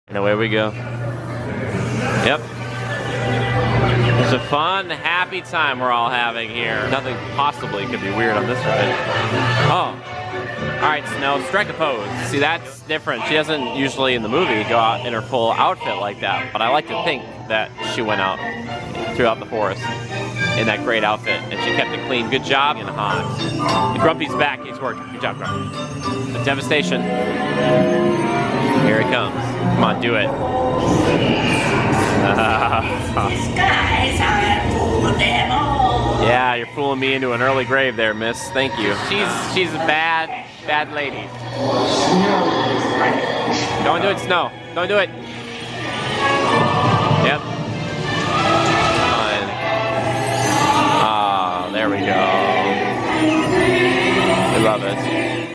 A truncated version of this terrifying ride!